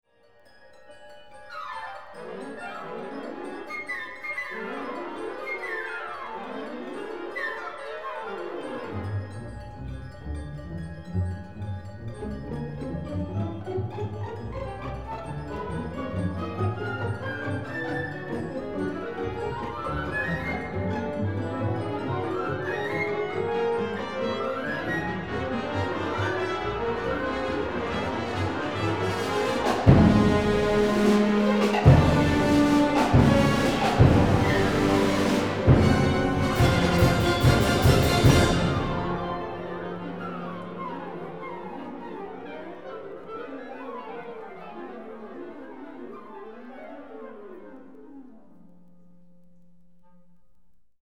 Instrumentation: orchestra